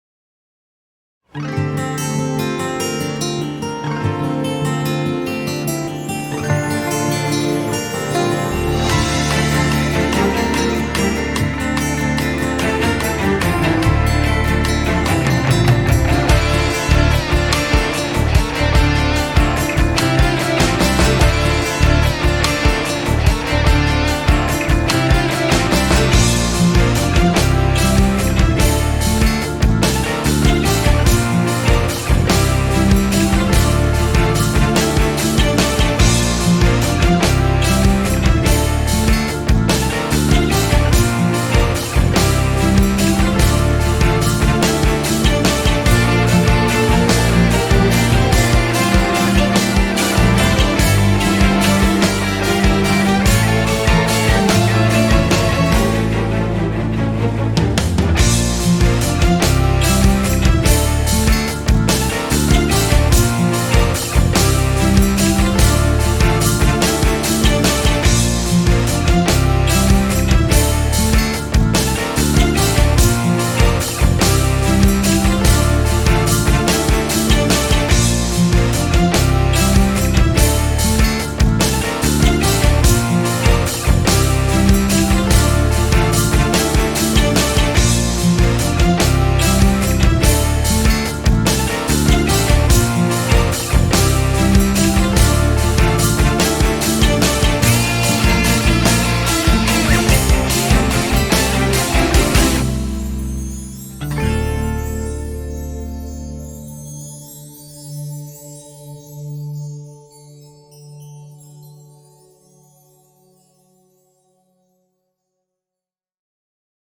tema dizi müziği, mutlu rahatlatıcı enerjik fon müziği.